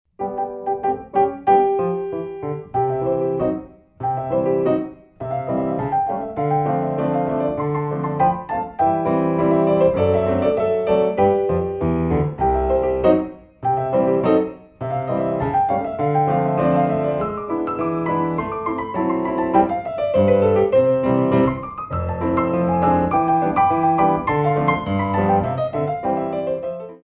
Pas de Pointes